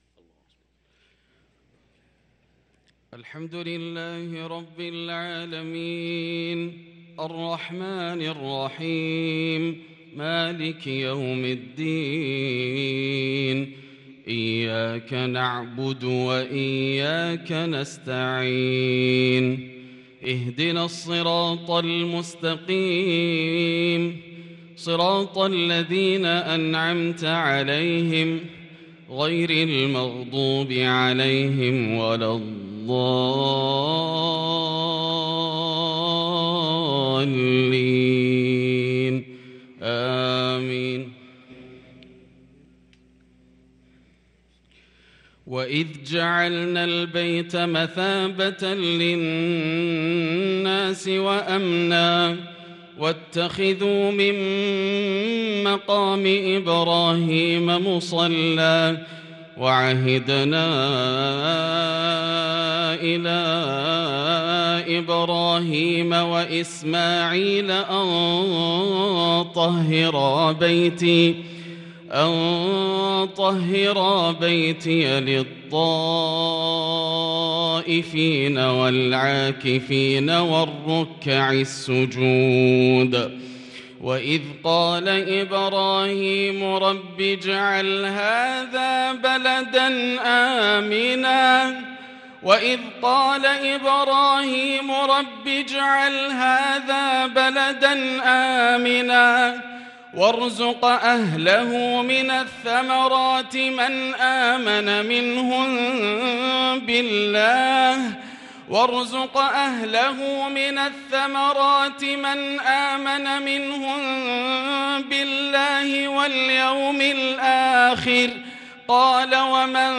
صلاة الفجر للقارئ ياسر الدوسري 13 ذو الحجة 1443 هـ
تِلَاوَات الْحَرَمَيْن .